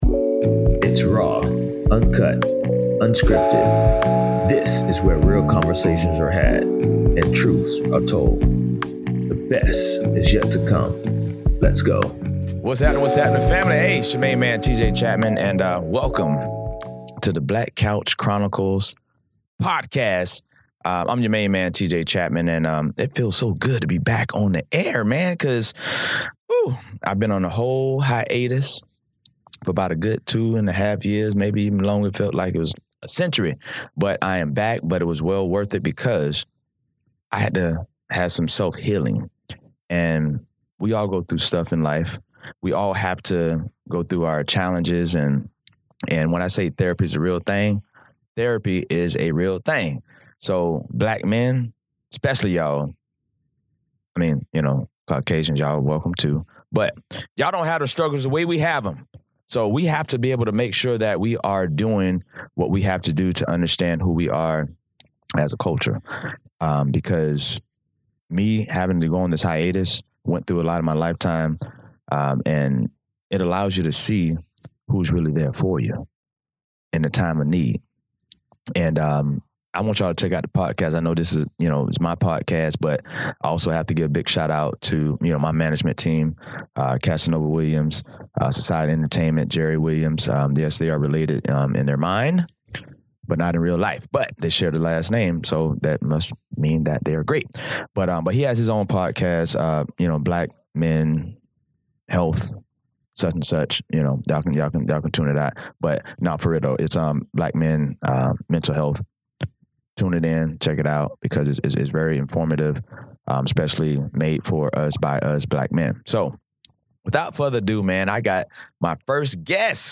Interview with Orlando Fl District Five Commissioner Shan Rose